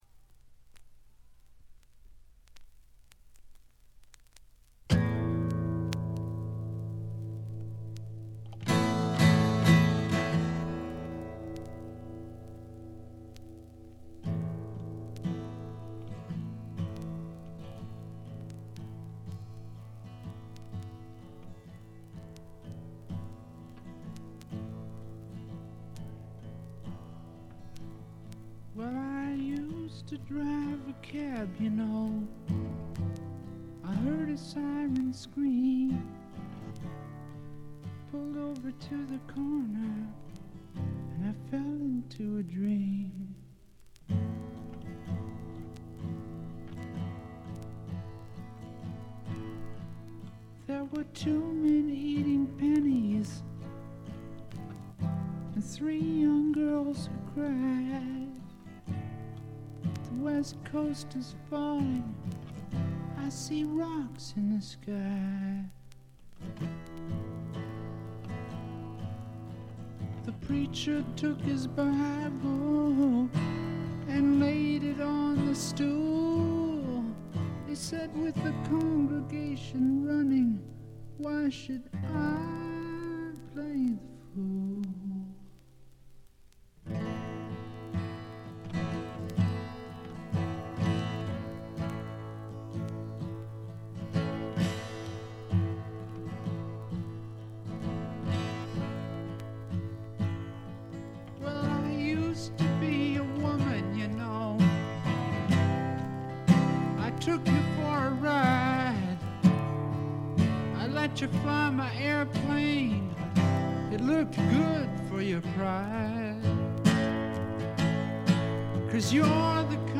静音部ところどころでチリプチ。散発的なプツ音少々。
試聴曲は現品からの取り込み音源です。